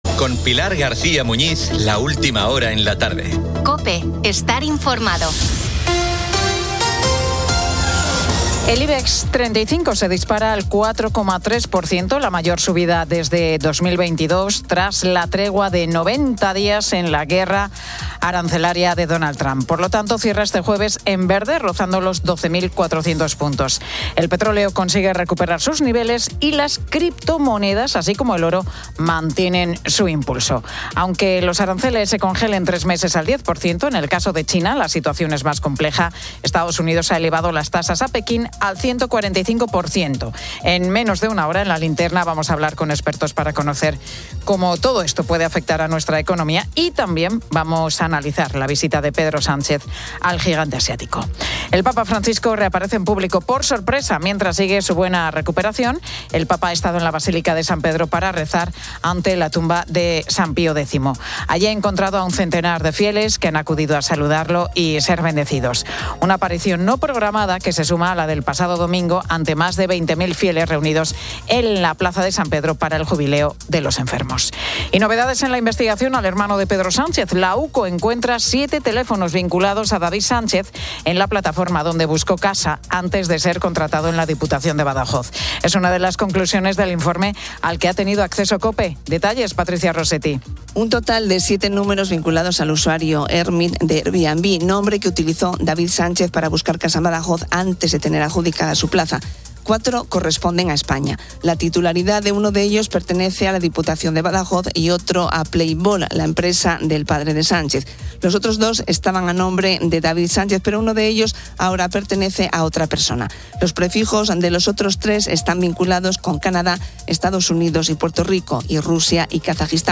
Con Pilar García Muñiz, la última hora en la tarde.